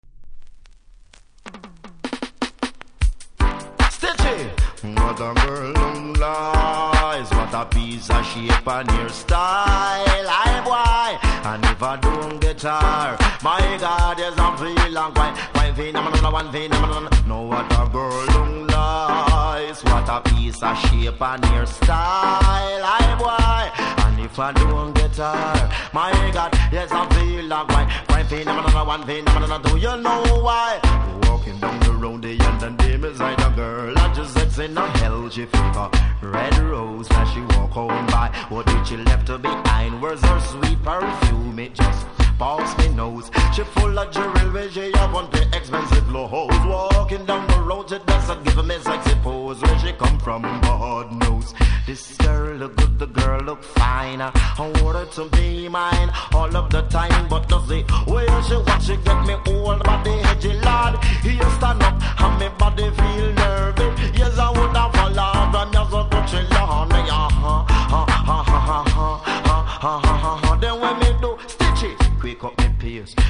REGGAE 80'S
多少うすキズありますが音は良好なので試聴で確認下さい。